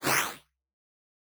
22_Slash_04.wav